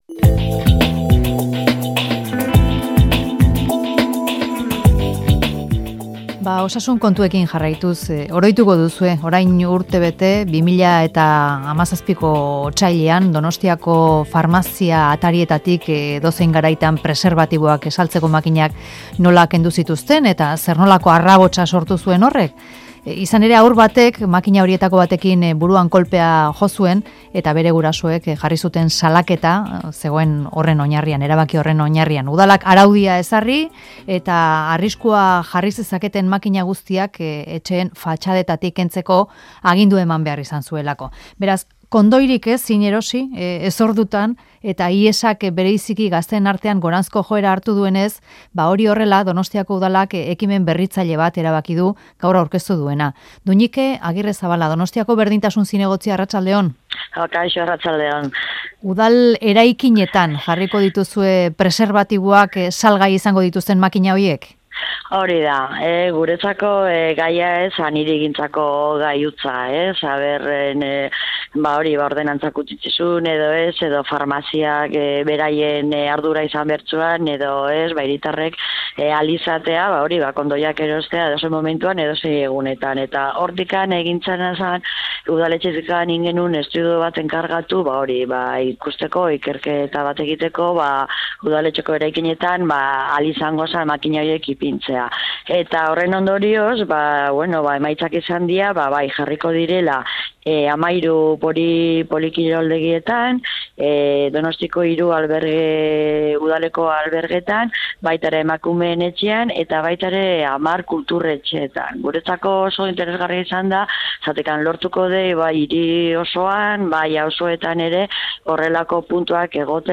Audioa: Donostiako 40 bat udal eraikinetan antisorgailu makinak jarriko dituzte, farmazietako makinen osagarri. Duñike Agirrezabalaga Berdintasun zinegotziak eman ditu azalpenak.